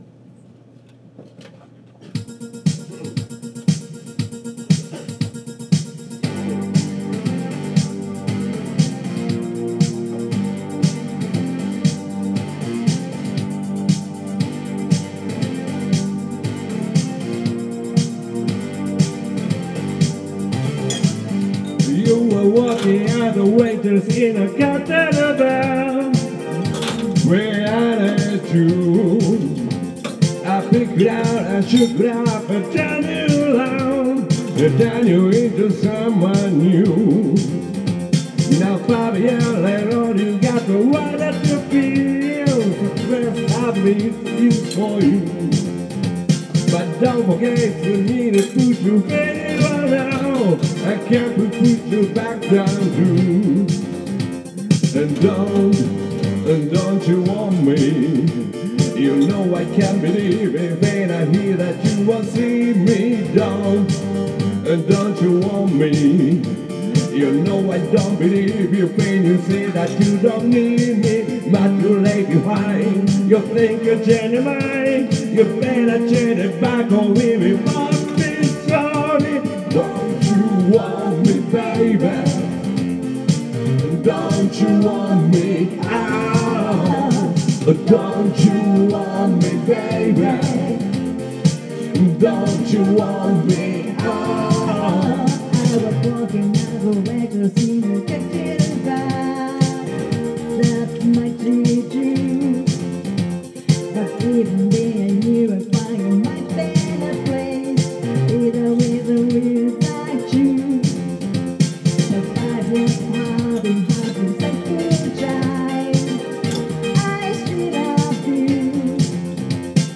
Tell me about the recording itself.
Duet & Chorus Night Vol. 12 TURN TABLE